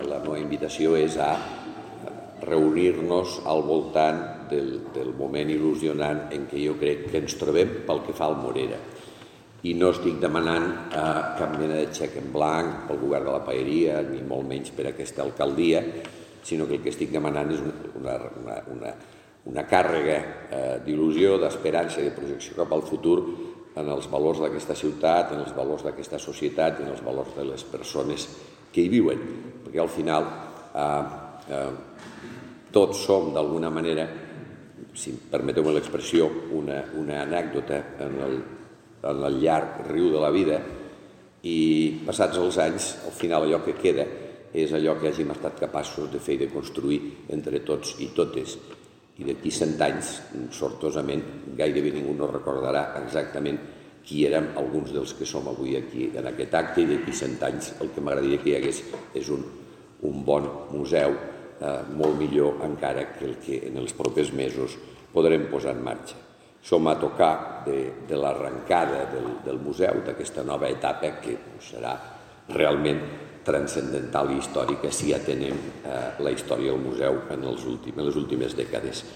tall-de-veu-del-paer-en-cap-miquel-pueyo-sobre-el-nou-morera